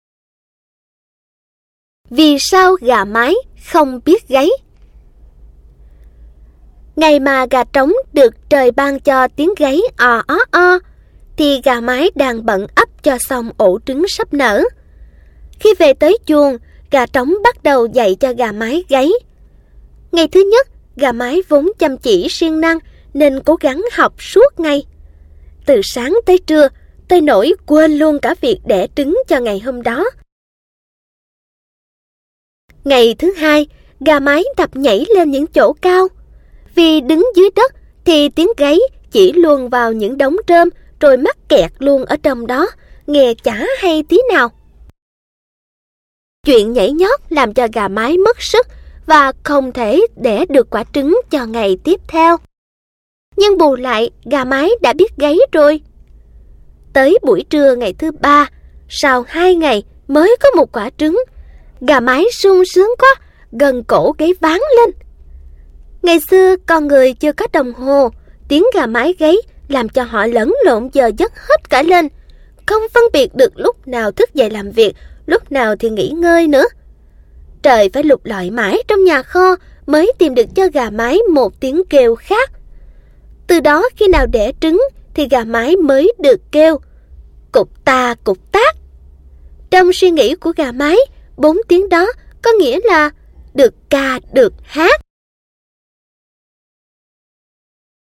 Sách nói | Vì sao gà mái không biết gáy